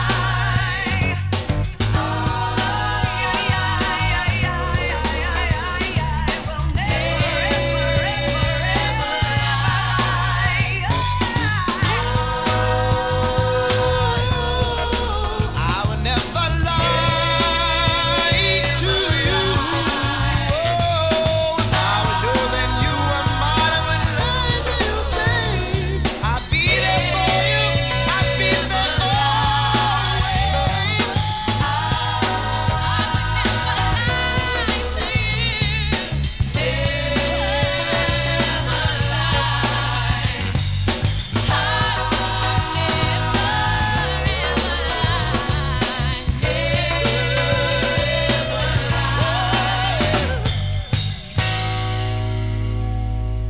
8 bit mono
From the Demo Tape
showing his vocal range !